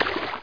waterrun2.mp3